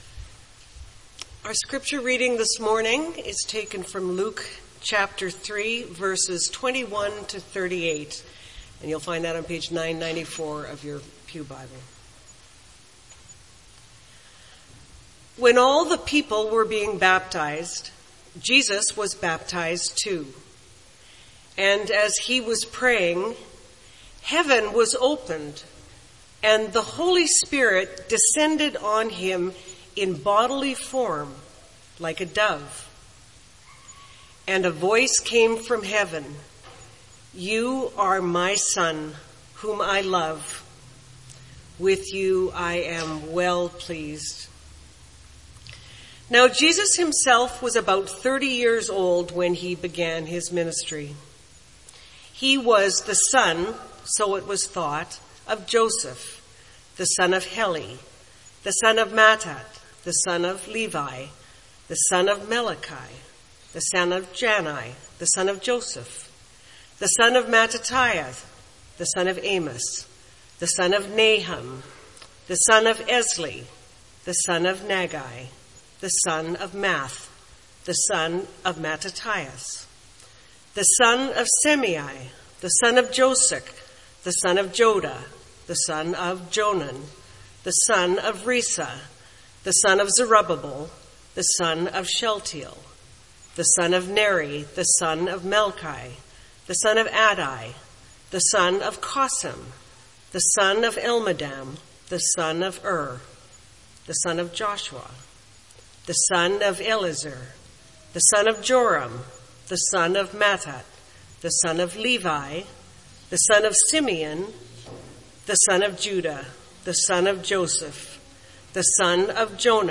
MP3 File Size: 22.4 MB Listen to Sermon: Download/Play Sermon MP3